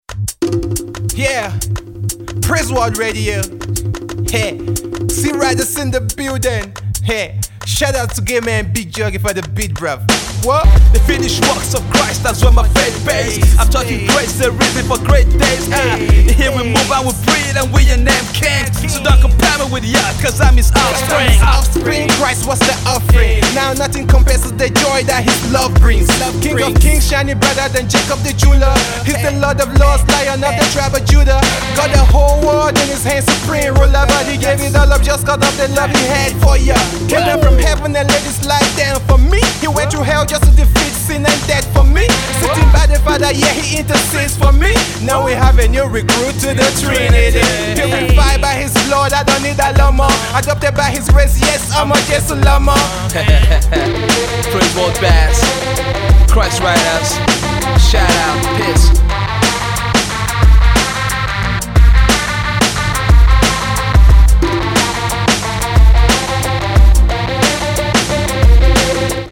Abuja based hip hop duo
is a rap group of 2 brothers